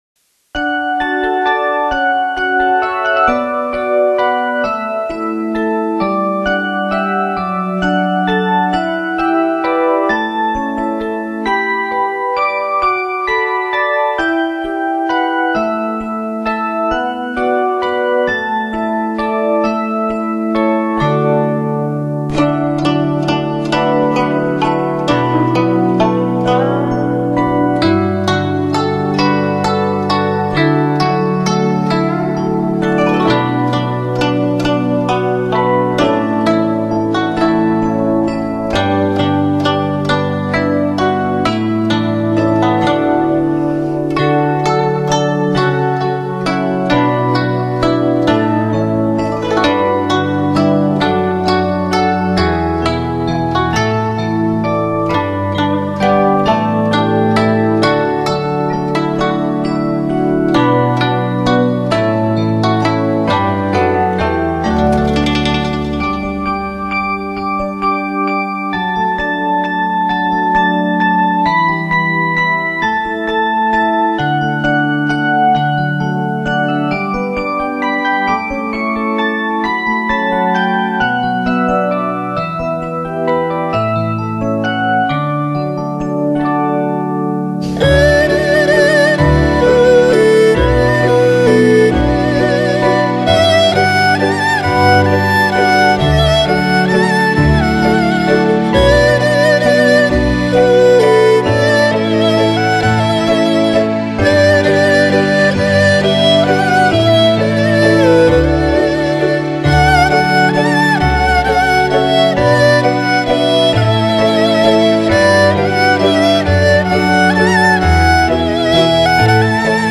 箏是中國傳統彈撥樂器，源於秦而盛於唐。
箏的音色華麗優美，明亮抒情，善於表現行雲流水的意境，常用於獨奏、合奏、重奏器樂和聲樂伴奏中。